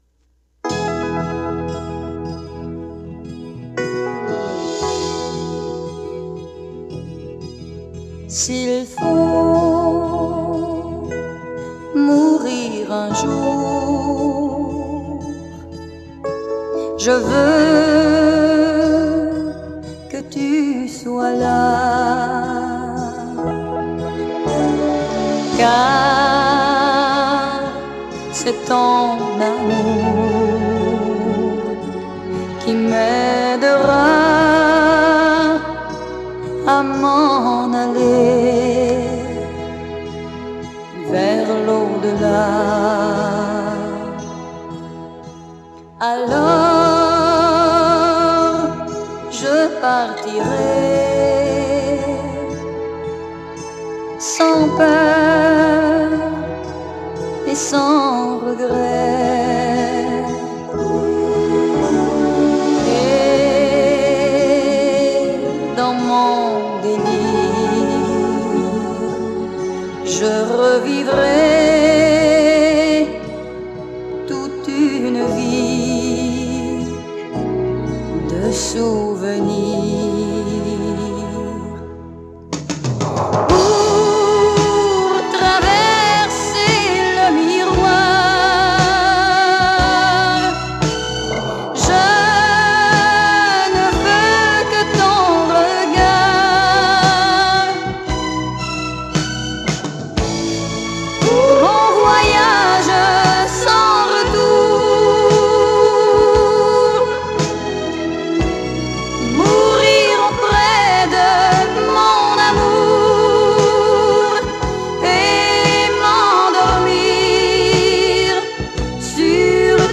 Son: stéréo
Enregistrement: Studio St-Charles à Longueuil